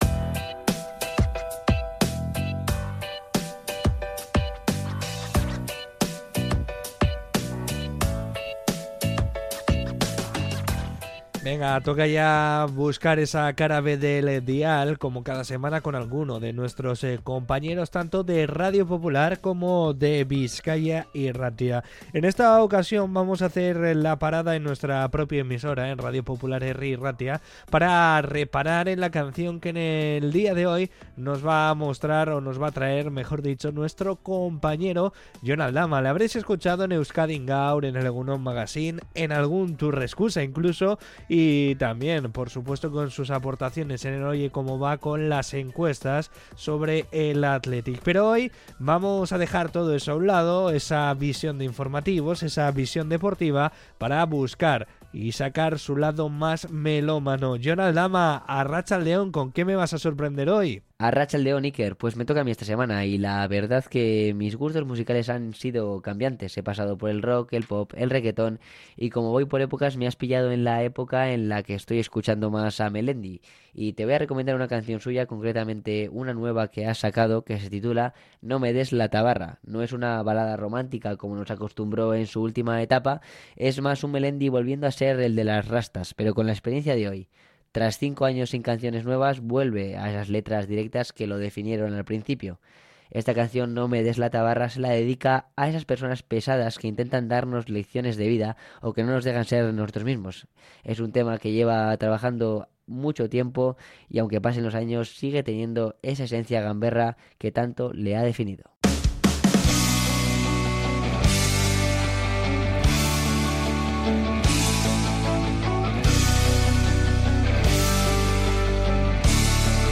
Podcast Música